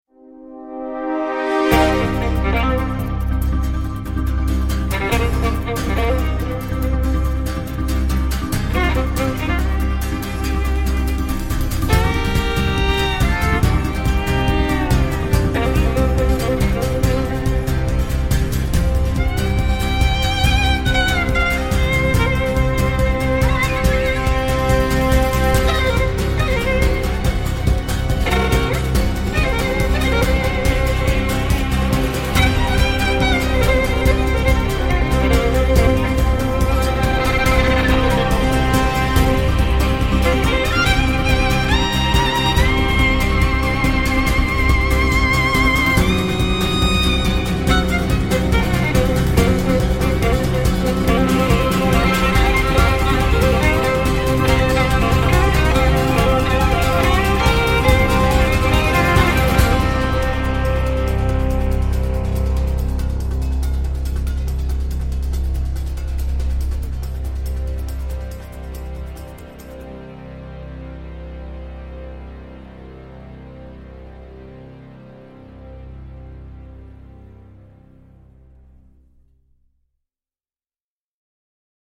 L’americana fatigué, avec sa guitare hantée
à commencer par les coups de crincrin.